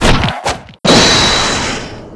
salute-double.wav